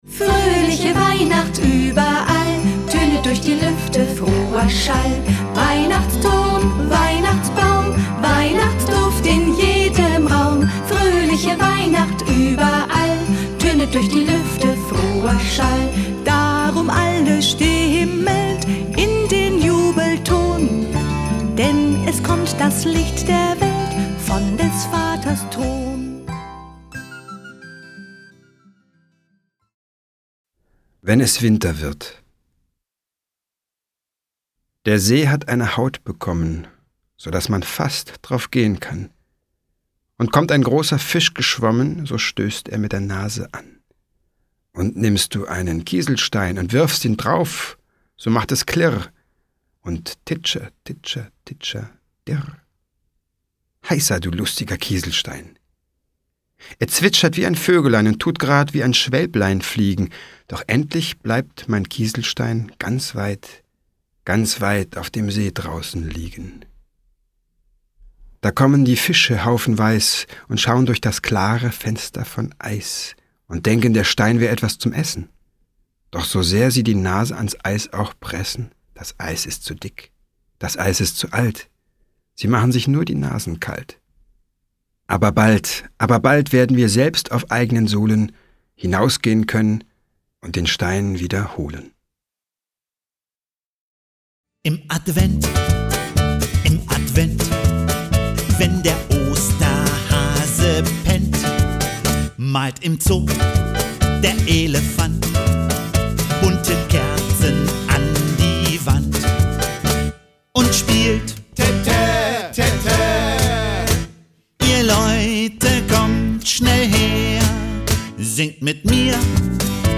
Lieder, Gedichte und Geschichten für die ganze Familie